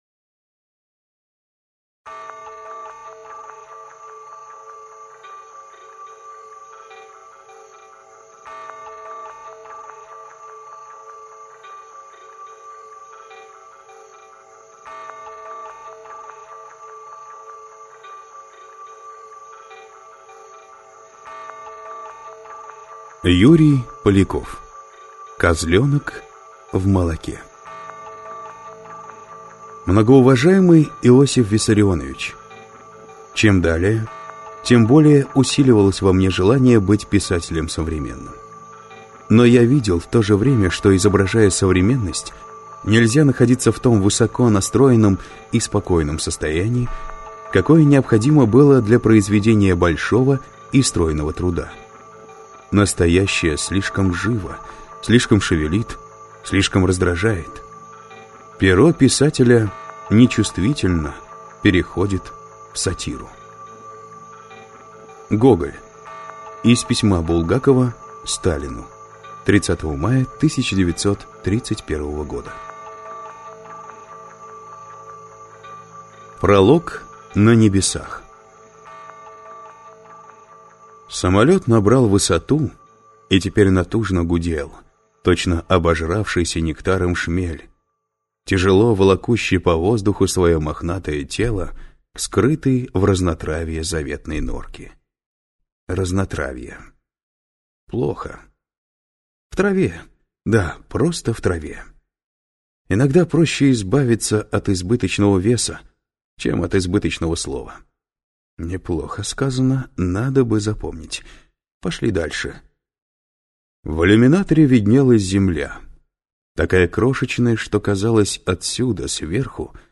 Аудиокнига Козленок в молоке | Библиотека аудиокниг